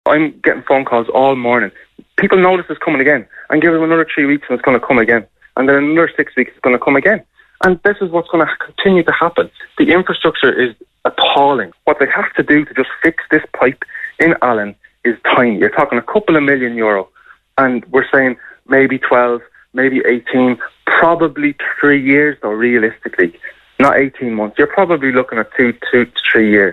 However, Cllr Aidan Farrelly said frustration among locals has reached boiling point.